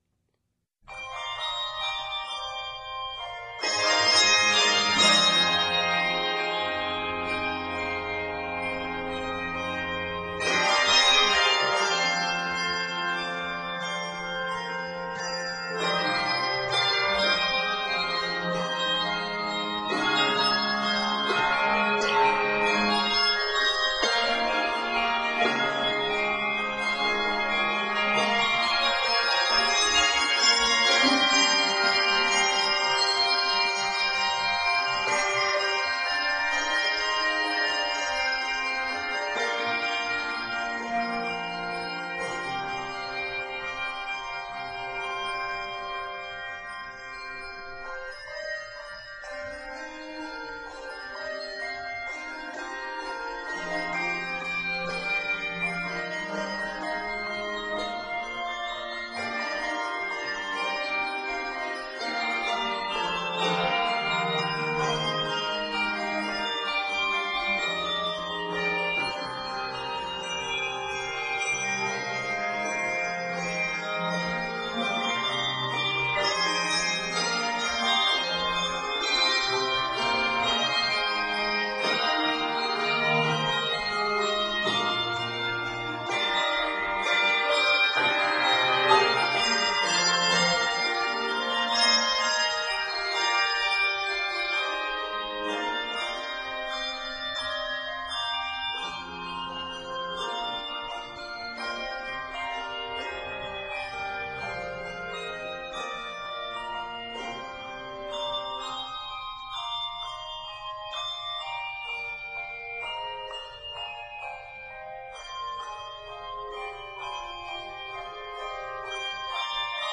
in a lively setting